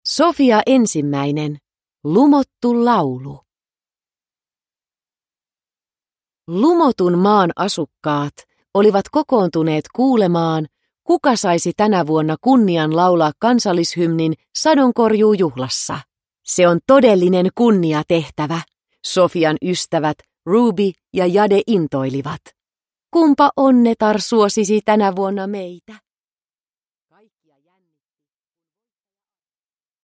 Sofia ensimmäinen. Lumottu laulu – Ljudbok – Laddas ner